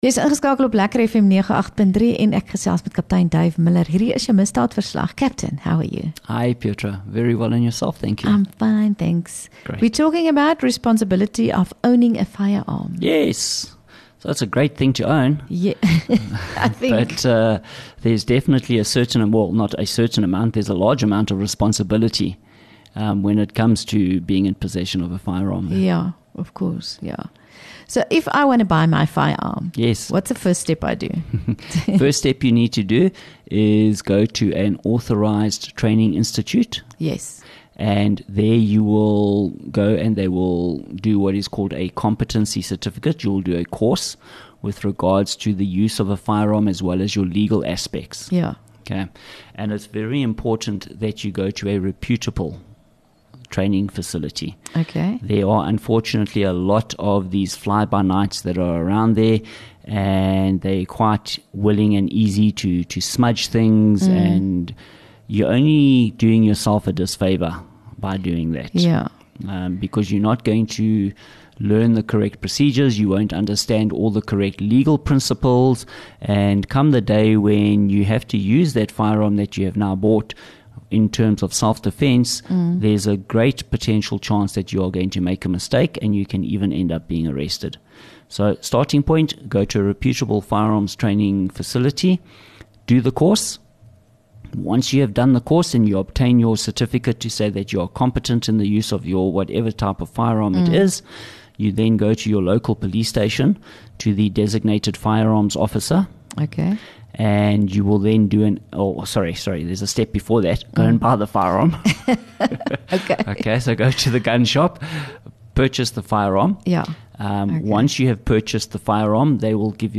LEKKER FM | Onderhoude 4 Jun Misdaadverslag